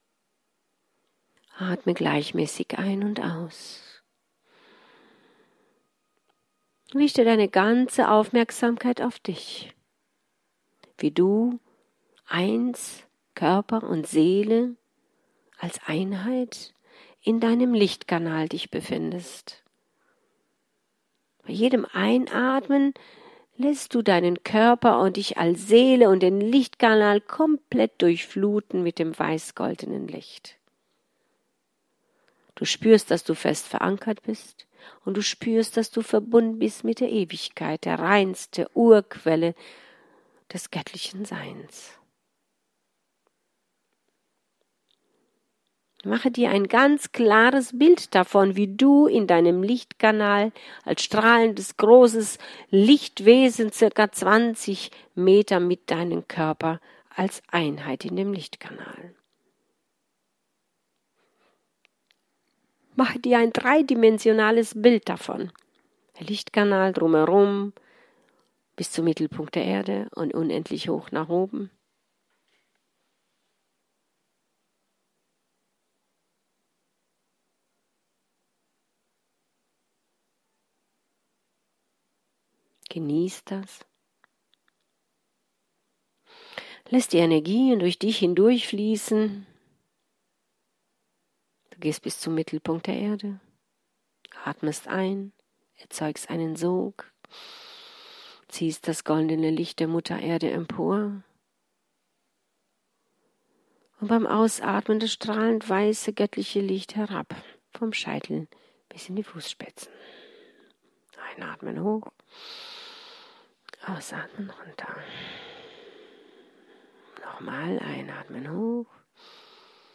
Im Augenblick sein, im SEIN sein - Channeling - Beziehung retten, Karmische Beziehung, Liebeskummer überwinden, Schlechtes Karma auflösen